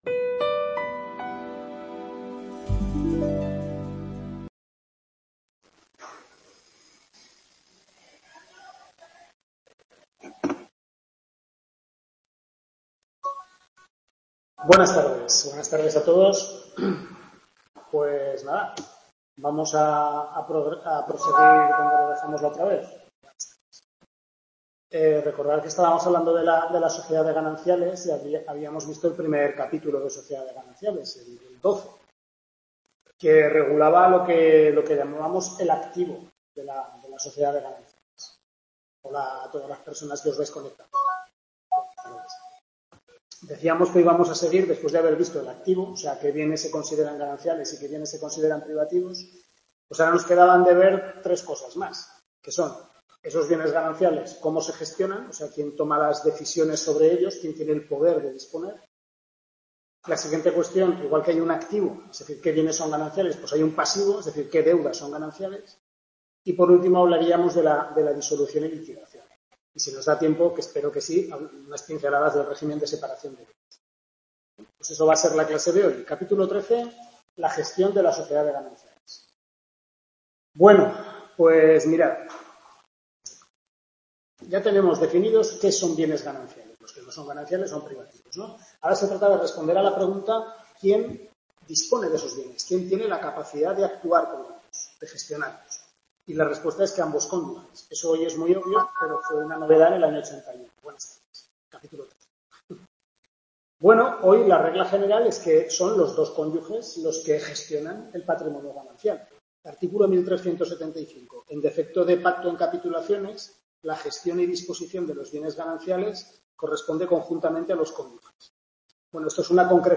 Tutoría 4/6, segundo cuatrimestre Civil I (Familia)